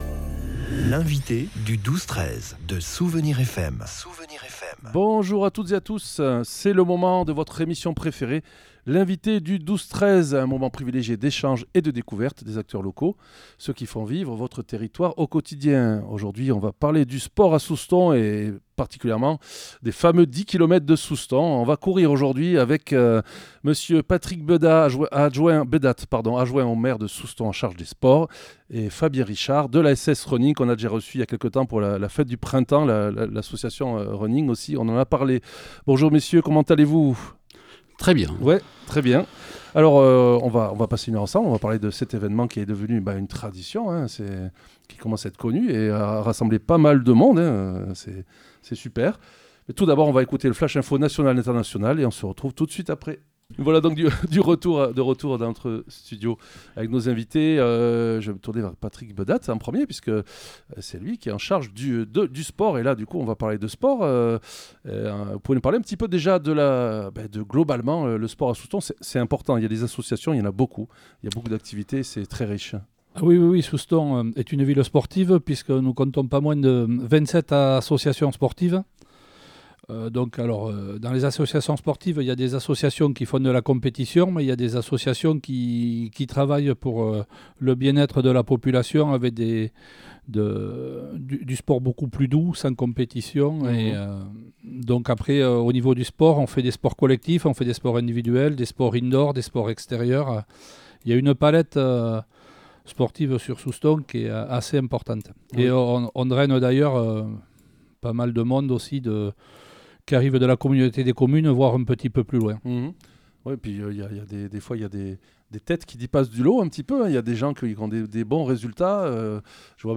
Dans la bonne humeur, nous avons parlé de l'organisation et de la logistique de cette nouvelle édition de la course qui ouvre les fêtes de Soustons.